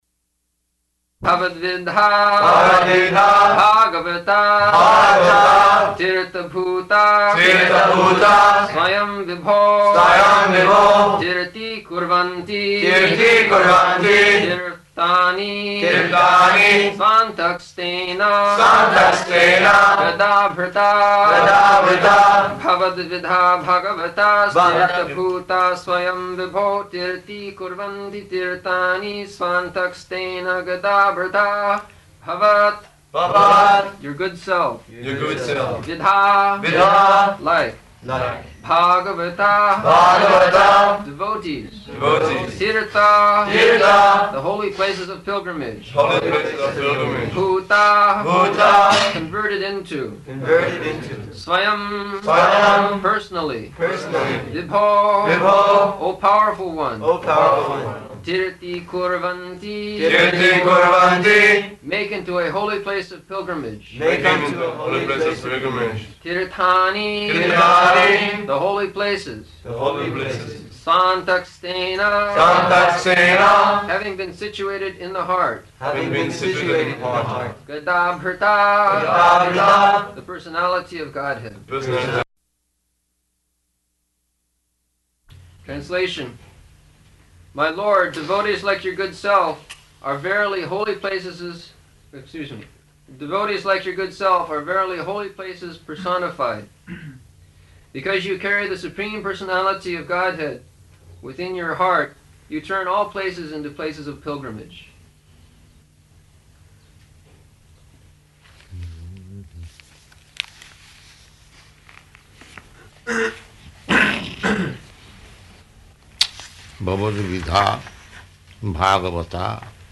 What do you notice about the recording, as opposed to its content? June 1st 1974 Location: Geneva Audio file